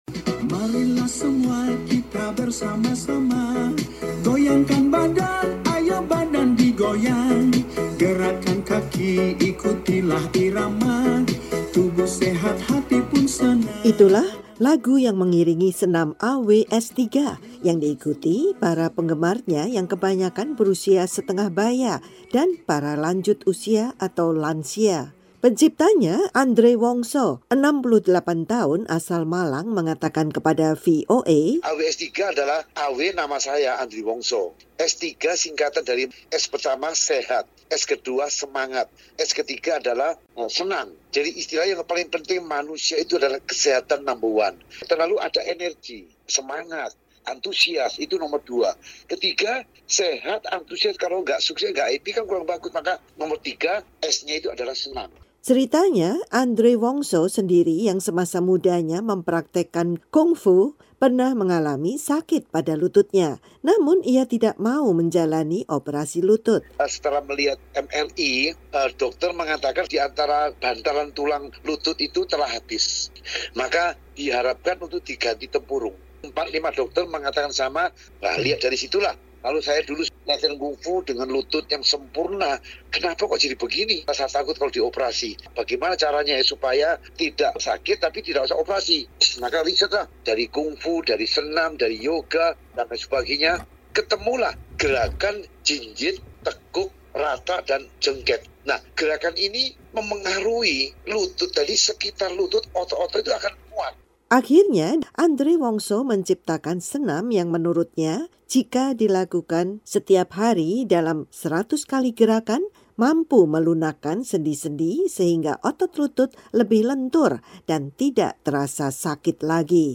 Itulah lagu yang mengiringi senam AW S3, yang diikuti para penggemarnya yang kebanyakan berusia setengah baya dan para lanjut usia atau lansia.